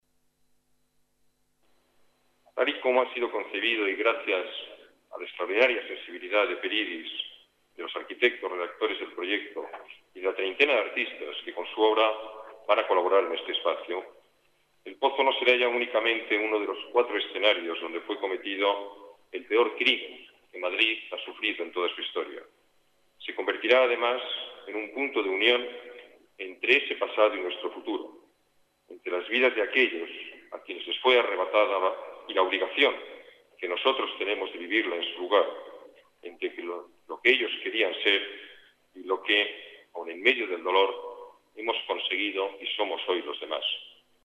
Nueva ventana:Palabras del alcalde, Alberto Ruiz-Gallardón